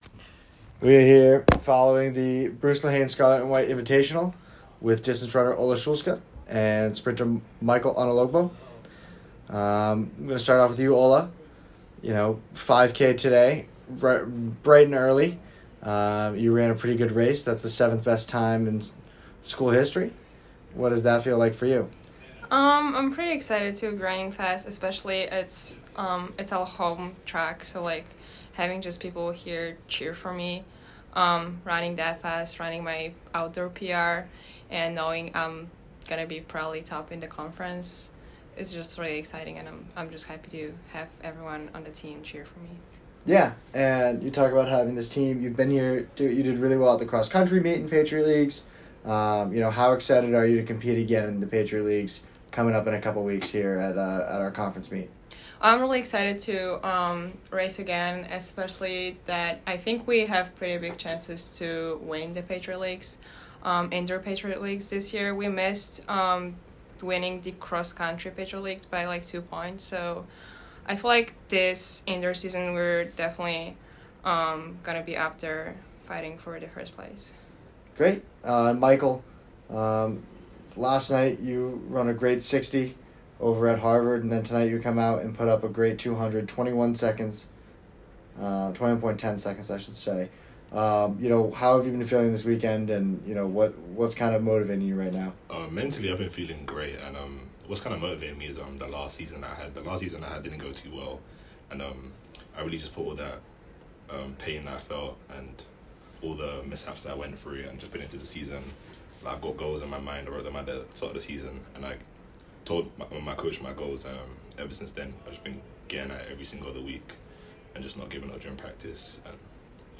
Track & Field / Scarlet & White Invitational Postmeet (2-8-25)